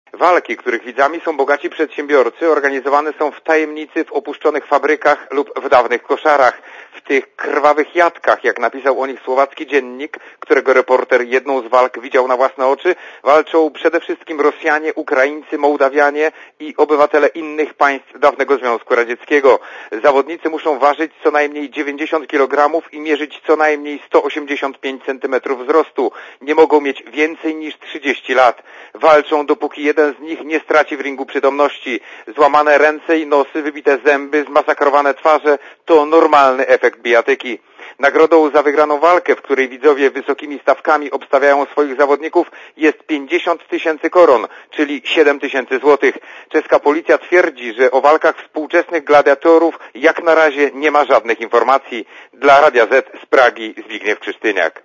Korespondencja z Czech (216Kb)